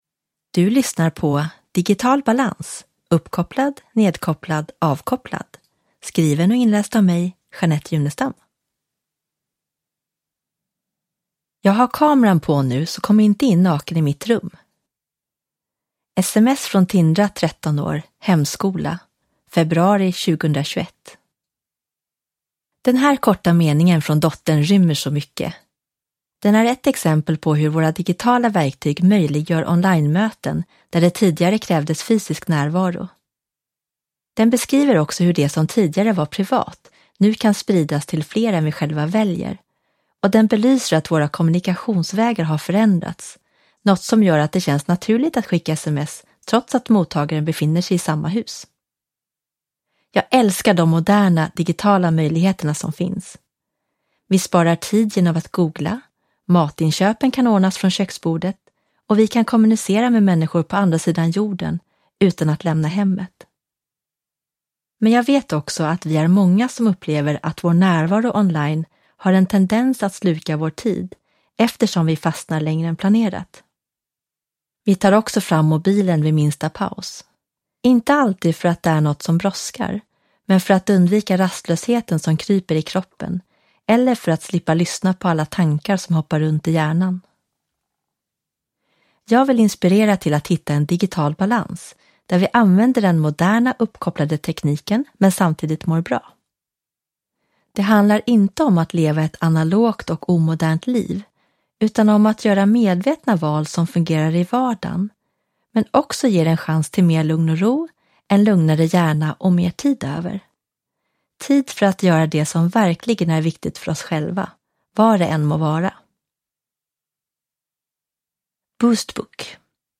BooztBook Digital balans: Uppkopplad,nedkopplad, avkopplad – Ljudbok